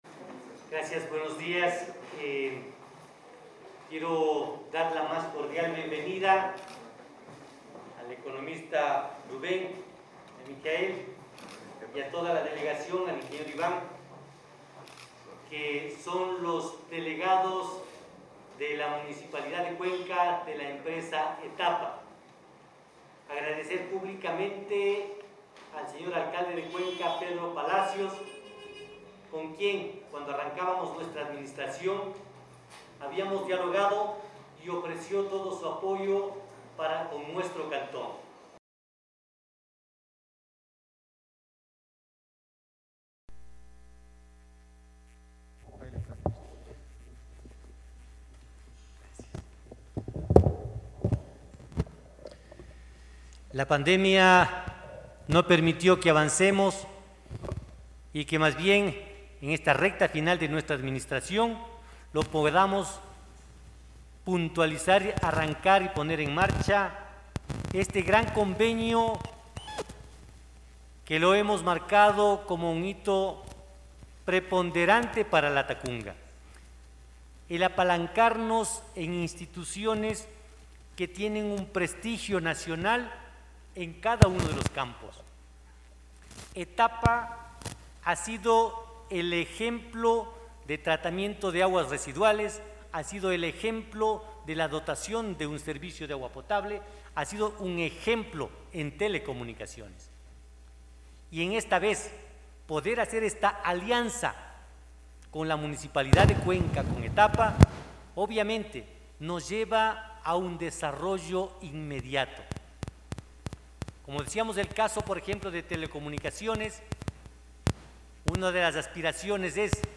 Byron-Cardenas-Alcalde.mp3